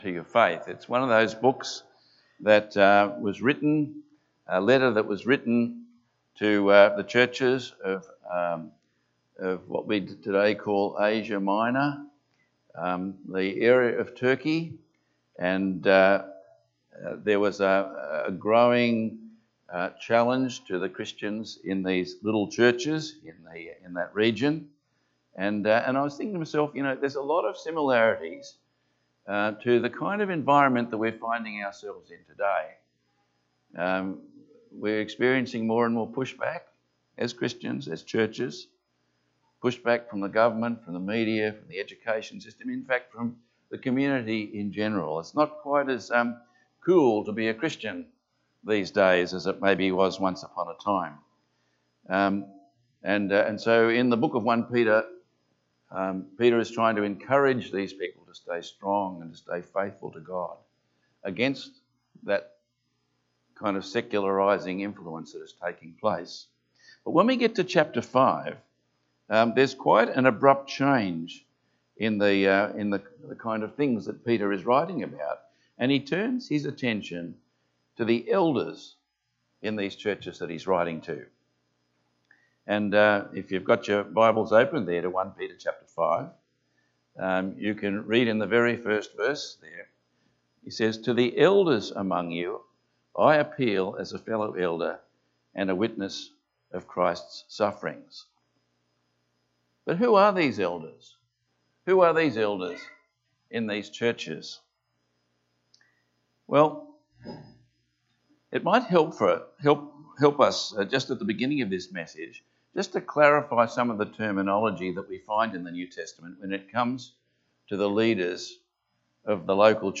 Passage: 1 Peter 5:1-4 Service Type: Sunday Morning